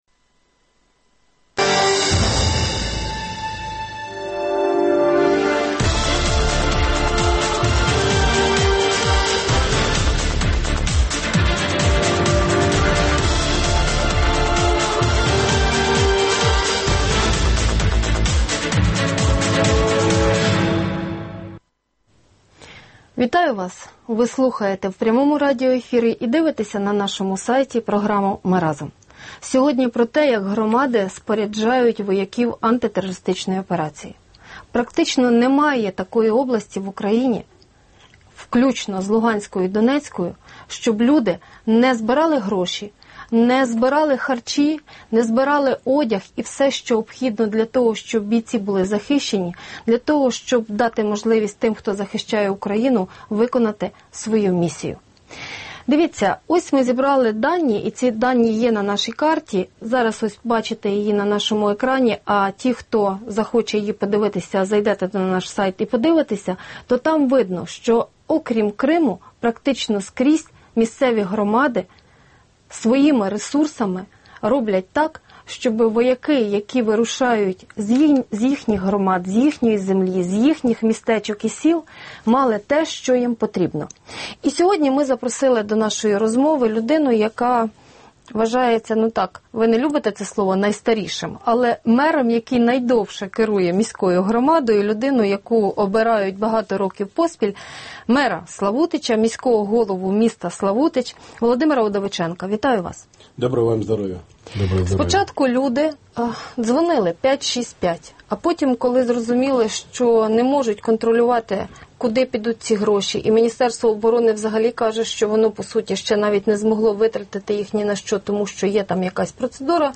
Міста й села, колективи й окремі люди збирають гроші, дістають бронежилети та каски. Про те, як громади споряджають своїх вояків - у розмові з міським головою Славутича Володимиром Удовиченко із 21:33 на Радіо Свобода.